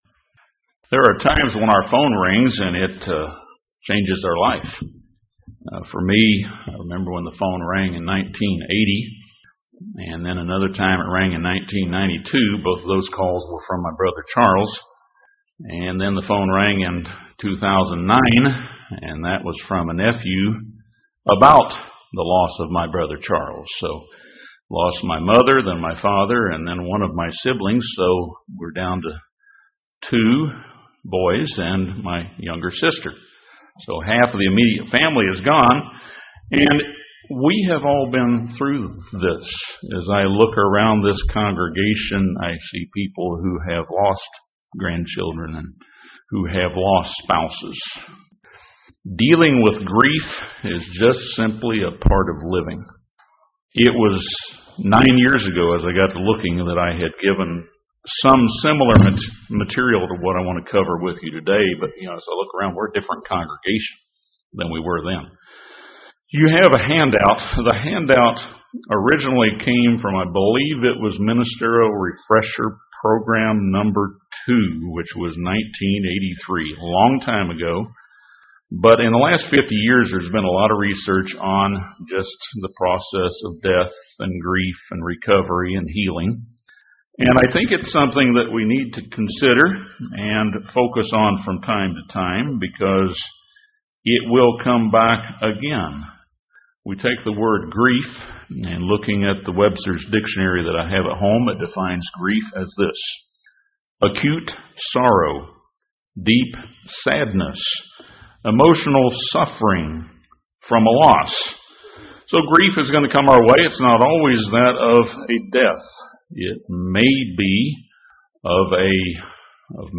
Each of us faces painful situations that bring grief to our lives. This sermon briefly touches on the common stages of grief but also provides suggestions to help in the healing of grief.
Given in Gadsden, AL Huntsville, AL